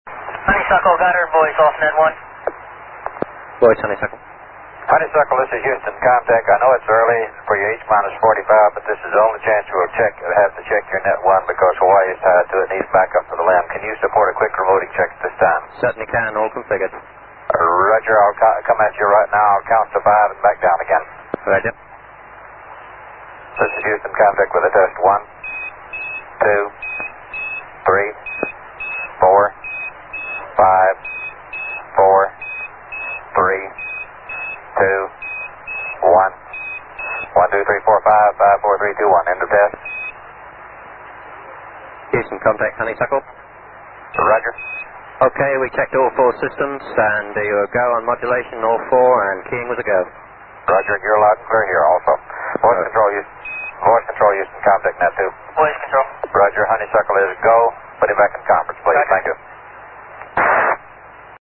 2 tonalités étaient utilisées dans le système "Quindar", chacune étant une onde sinusoïdale pure d’une durée de 250 millisecondes. La tonalité d'introduction, générée à 2,525 KHz, simulait l'appui sur le bouton PTT, tandis que la tonalité de terminaison, légèrement abaissée à 2,475 kHz, simulait son relâchement.
Cela donnait des transmissions typiques avec un "bip", suivi de la voix du Capcom, un autre "bip", puis la voix des astronautes.
Exemple de "Quindar tones"
quindar.mp3